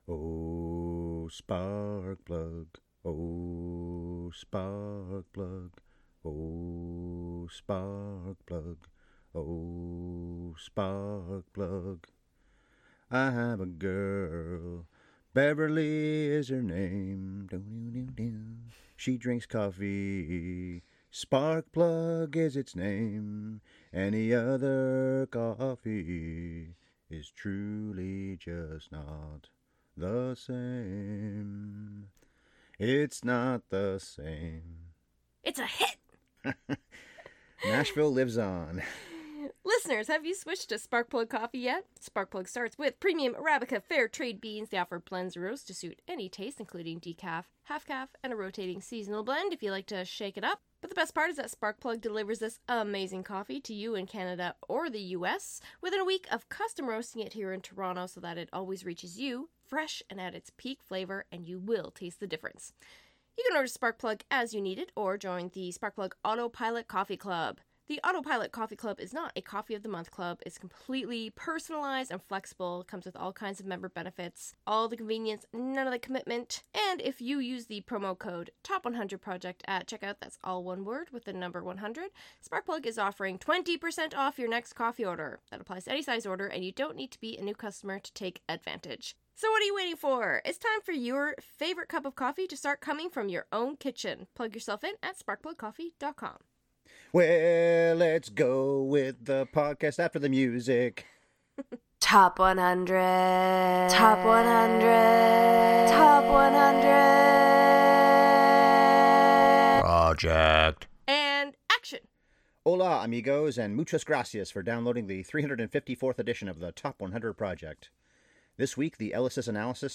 Welllllll, let’s go and shake (or stomp) it up in our 354th edition as we chit chat about Ritchie Valens, the beloved Mexican-American teenaged pop star whose career was cut tragically short. With the ending of a fateful plane crash hovering over the whole story, it’s probably inevitable that writer/director Luis Valdez was not going to make a fun movie, but we had a lot of giggles in this podcast anyway. And we sang…often!